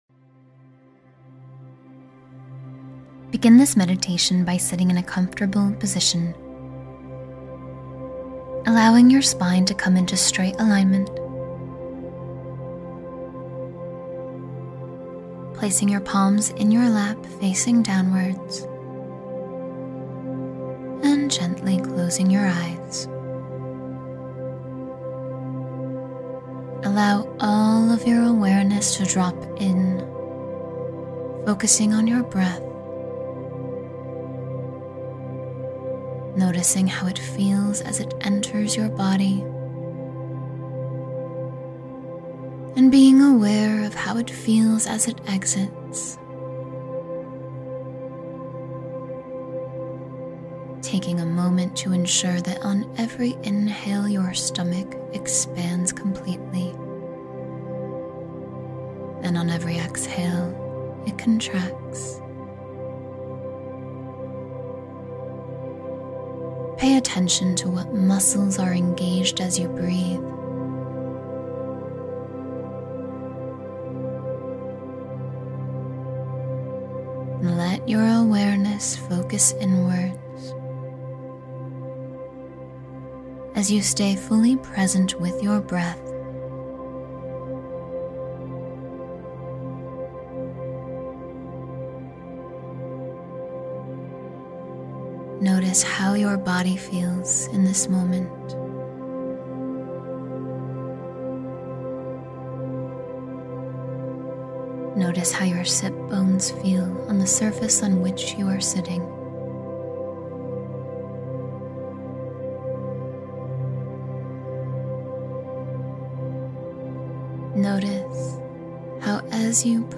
Release Your Greatness — 10-Minute Transformational Meditation